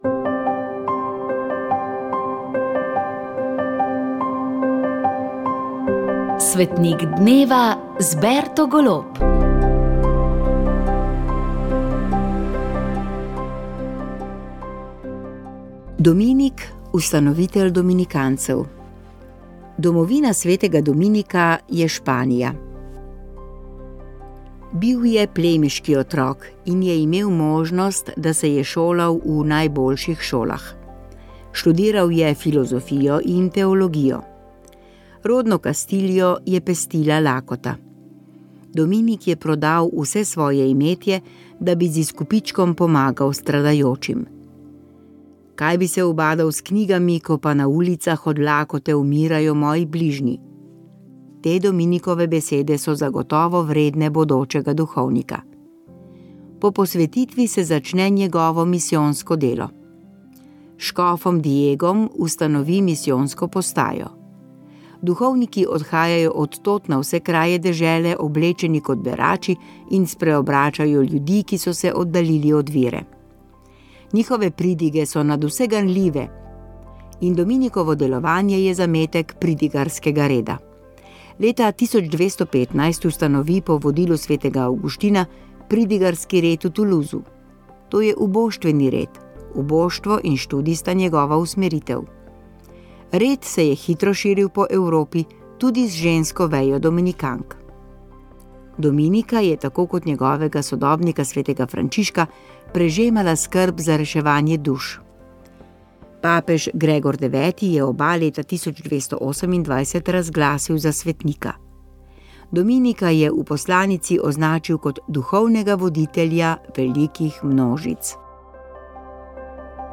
Informativni prispevki